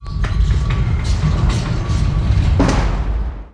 ind_lift2.wav